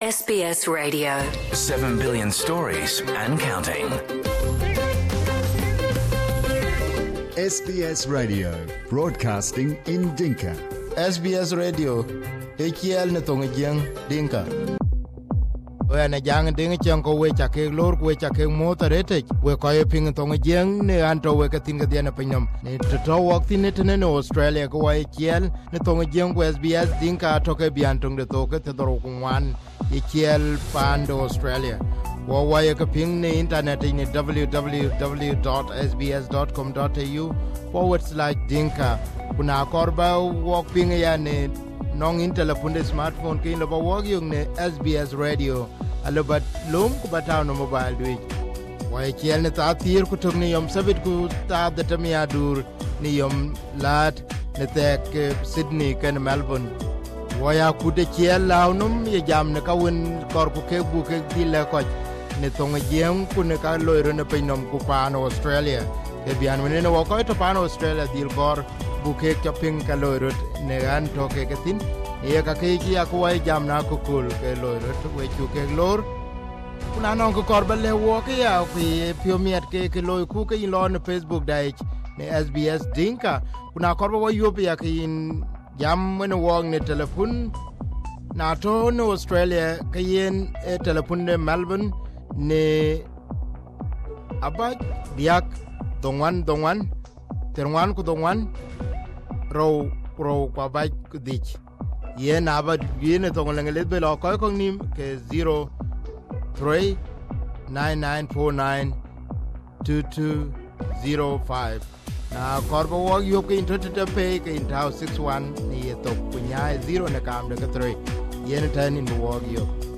will go one on one with the former President in this part one. Mengistu still lives in exile and still wanted by Ethiopia on human rights abuses during his times. in South Sudan; he is considered a hero.